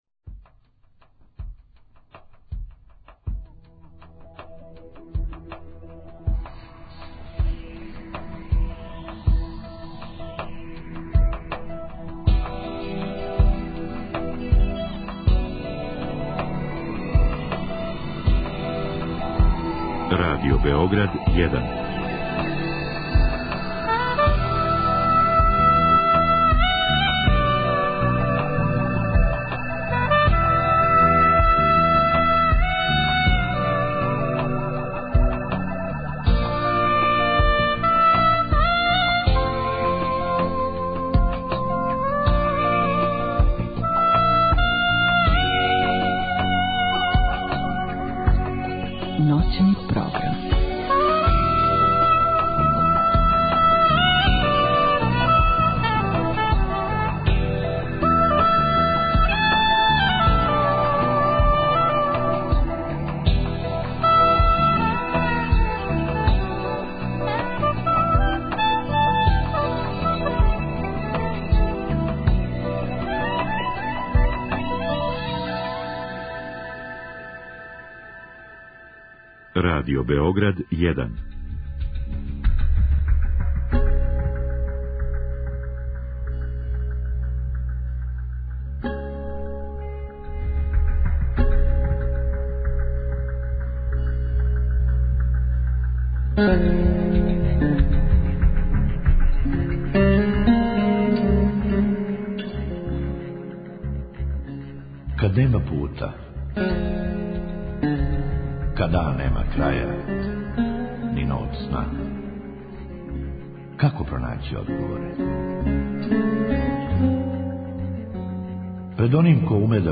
У другом сату емисије, наше слушатељке и слушаоци имају прилику да се директно укључе са коментарима, предлозима и проблемима који се односе на психолошки свет, међу свим другим световима.